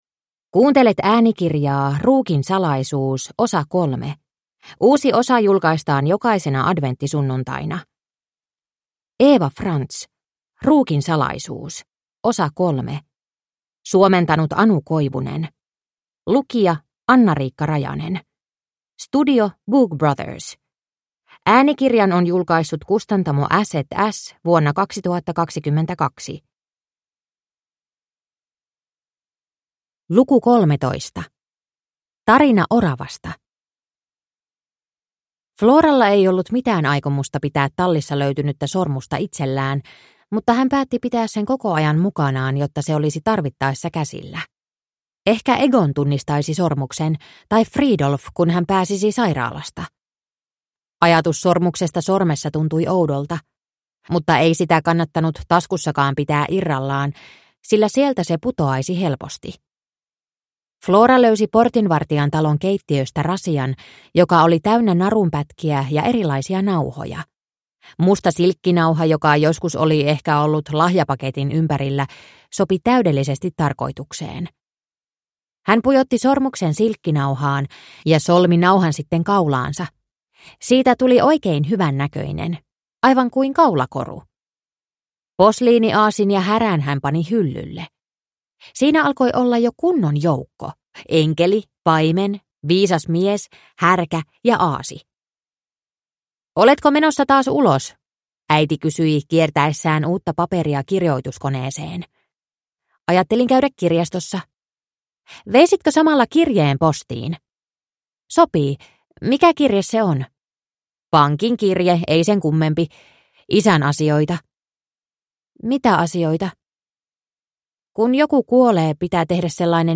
Ruukin salaisuus osa 3 – Ljudbok – Laddas ner